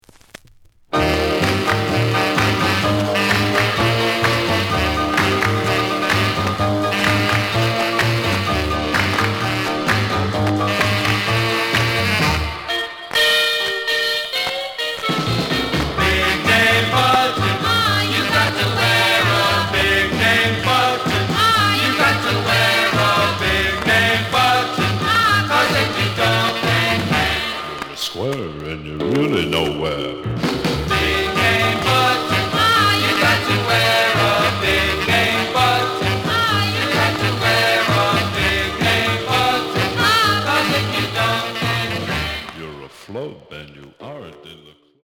The audio sample is recorded from the actual item.
●Genre: Rhythm And Blues / Rock 'n' Roll
Noticeable noise on B side due to scratches.